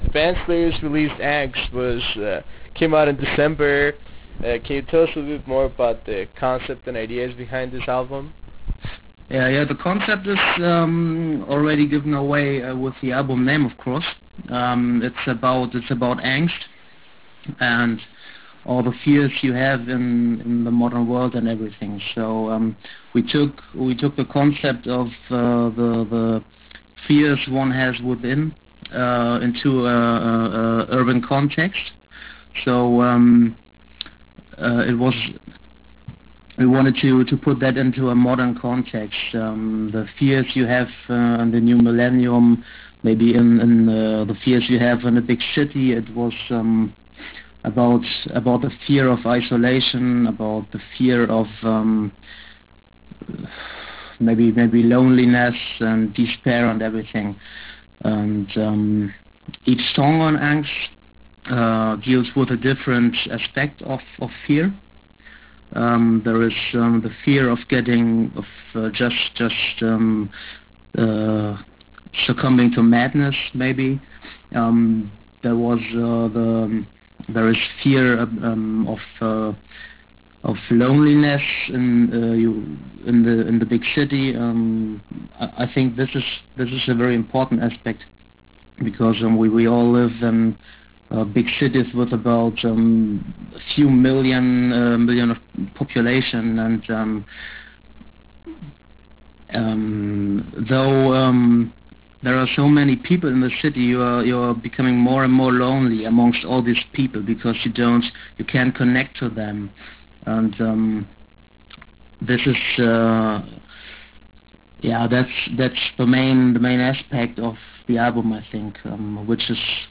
Interview with Todtgelichter